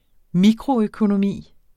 Udtale [ ˈmikʁo- ]